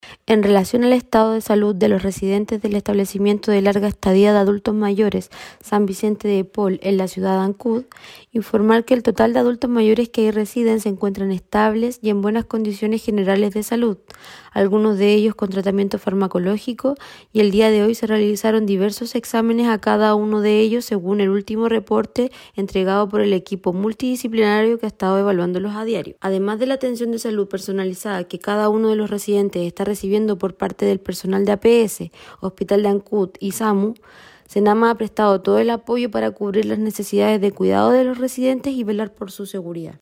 En cuanto a la condición de salud de los internos de este recinto, la jefa de la Autoridad Sanitaria en Chiloé, María Fernanda Matamala, expresó que se encuentran estables y siendo apoyados por equipos de salud primaria.